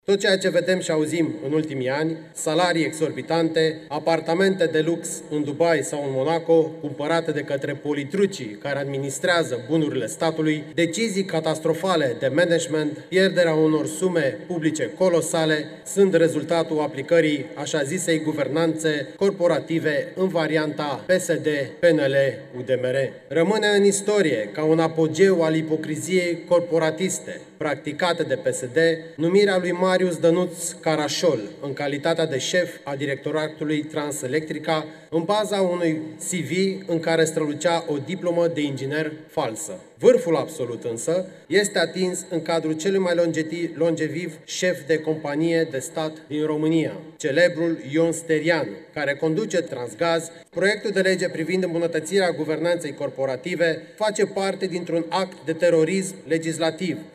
Deputatul AUR Cosmin Corendea a citit motiunea :